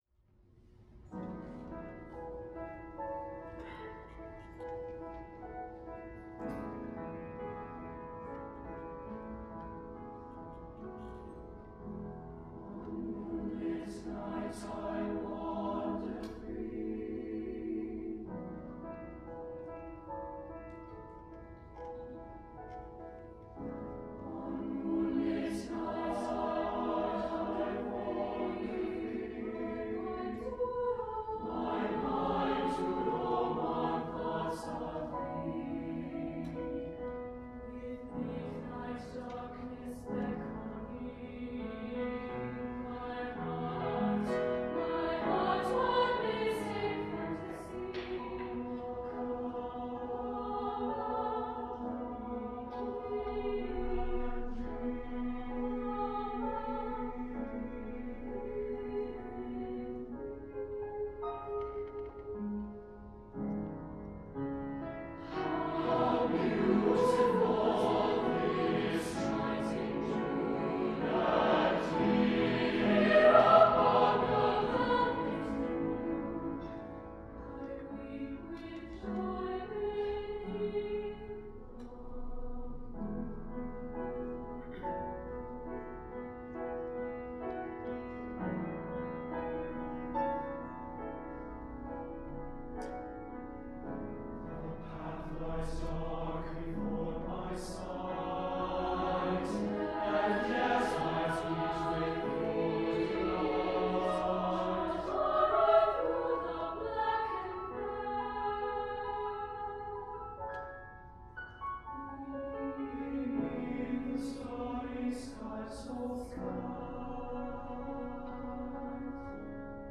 Symphonic Choir: Ballade to the Moon – Daniel Elder
12_ballade-to-the-moon_symphonic-choir.mp3